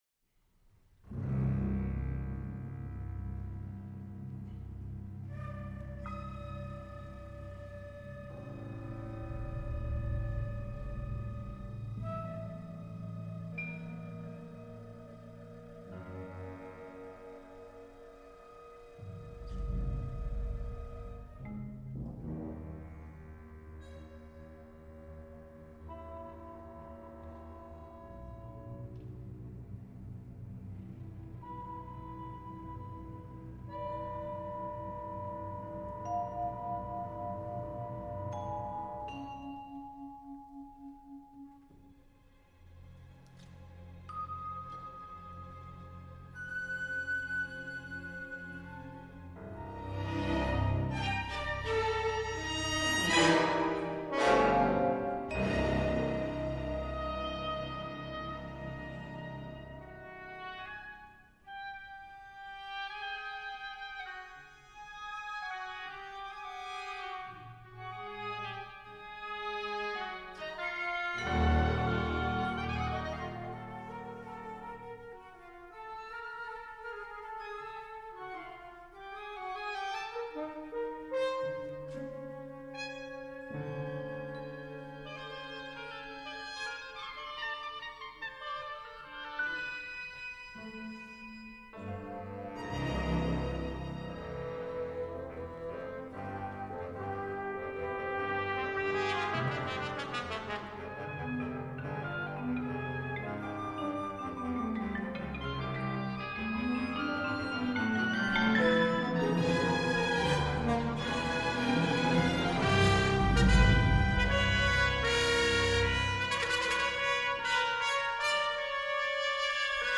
orchestral work
incorporates a computer generated track